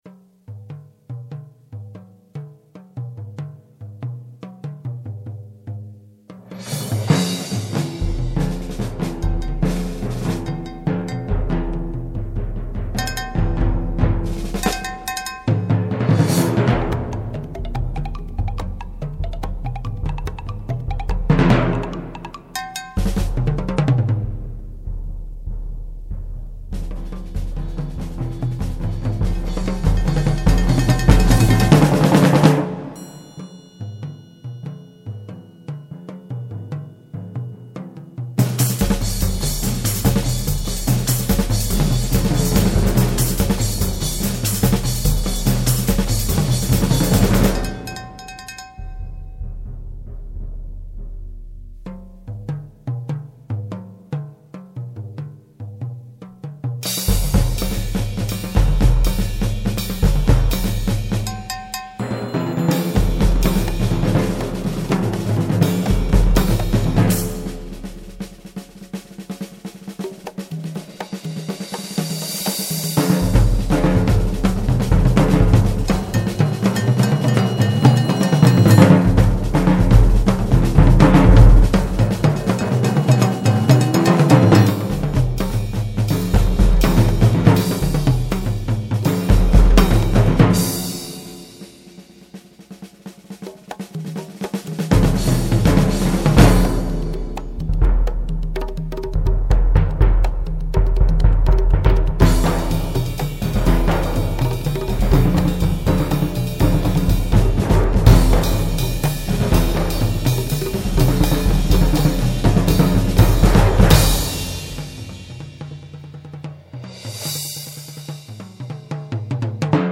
Voicing: Percussion Quintet